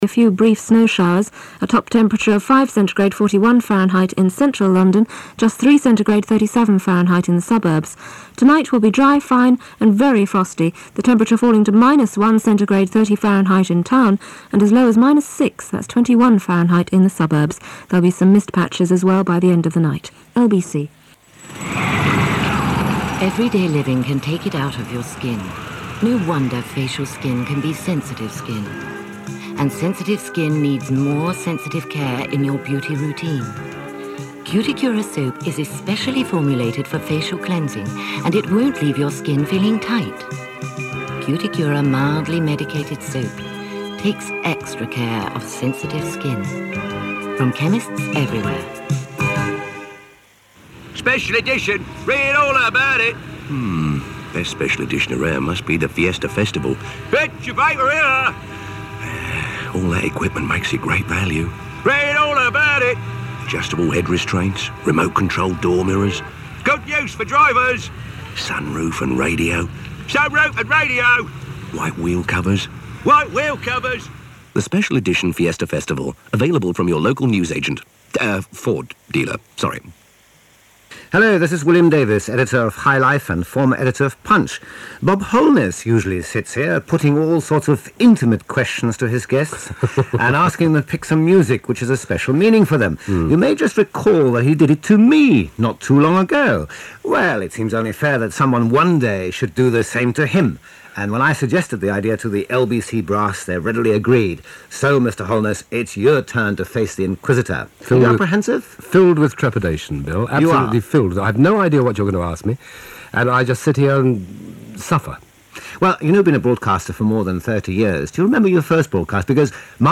1987Celebrit Interview.MP3